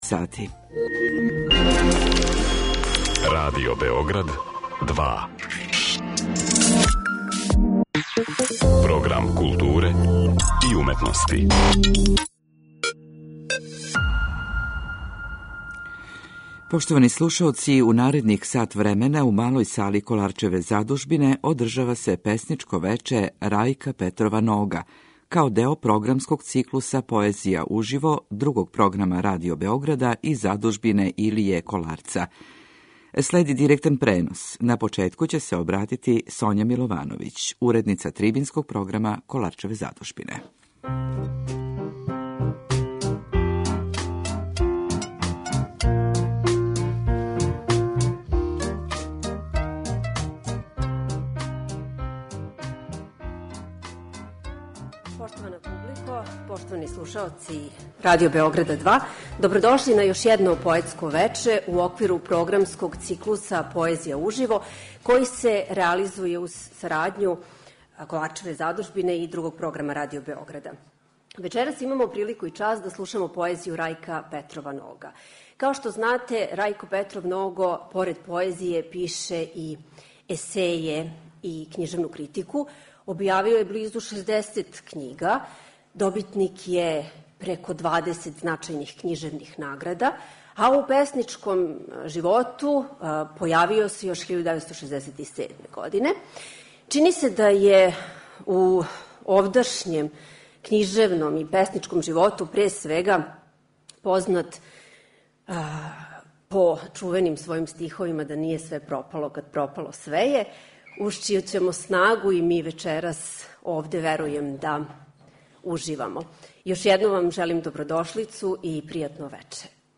Поетско вече Рајка Петрова Нога у оквиру програма 'Поезија уживо' Радио Београда 2 и Задужбине Илије М. Коларца, одржава се у суботу, 24. фебруара, од 19 часова. Директан пренос из Коларчеве задужбине можете пратити на нашим таласима.
Директан пренос на Радио Београду 2